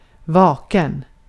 Ääntäminen
IPA: /ˈvɑːkˌɛn/